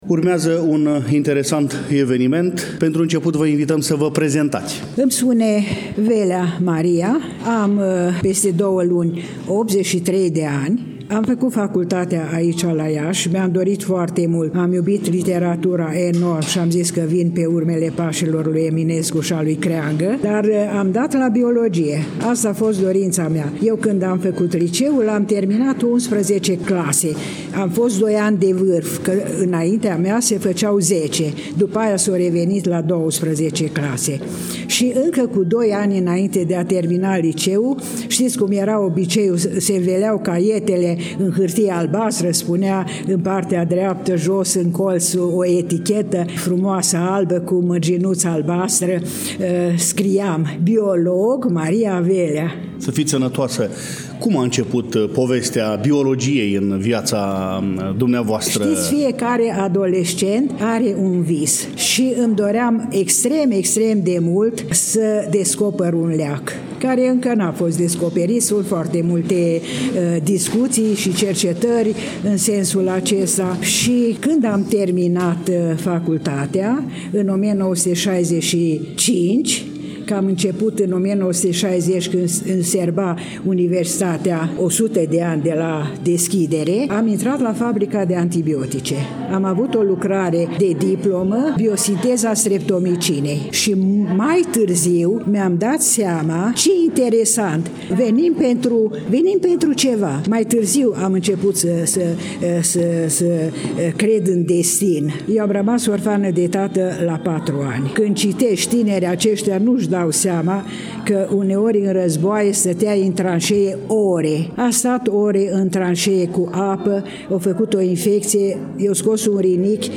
Dragi prieteni, vă spun că am avut prilejul să stau de vorbă cu un om excepțional.